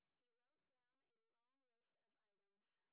sp11_street_snr20.wav